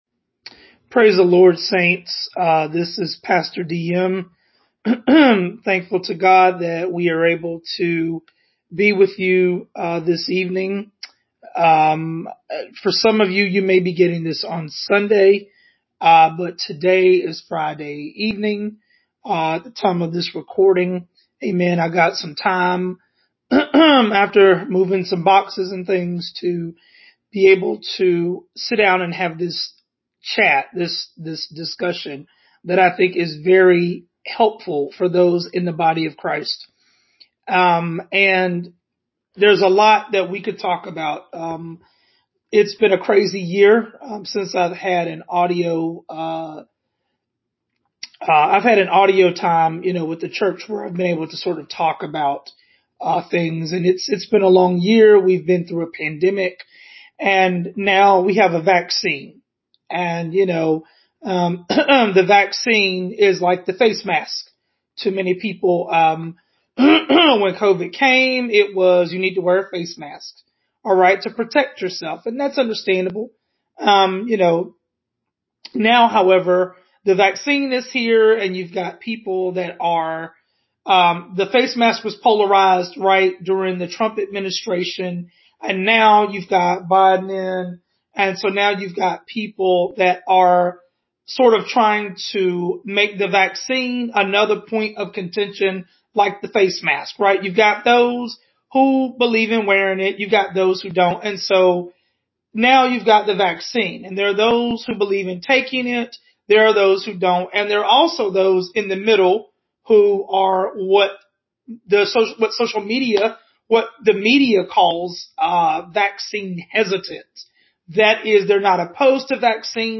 This discussion about pro-vaxxers and anti-vaxxers, as can be heard above, is designed to get both sides talking about the reasons as to why they want to take the vaccine and why someone would reasonably want to avoid taking the vaccine.